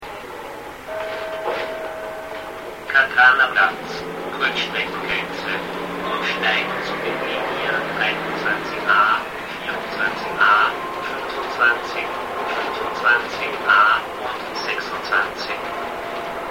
Haltestellenansagen